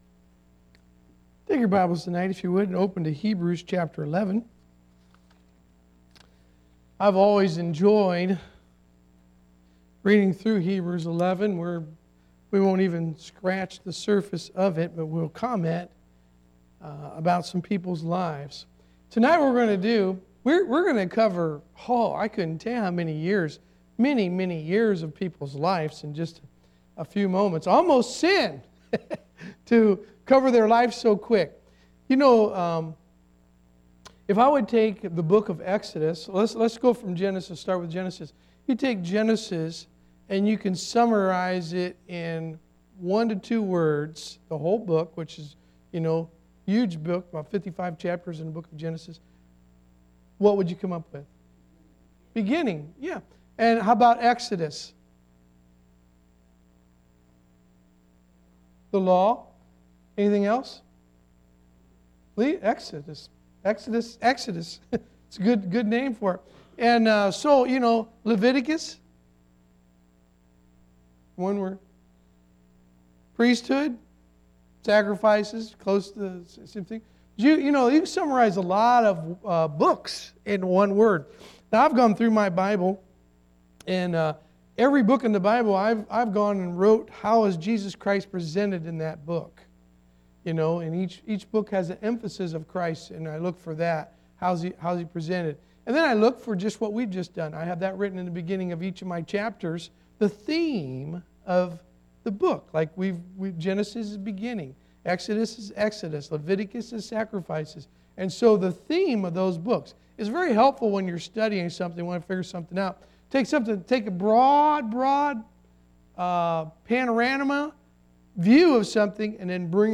Sermons «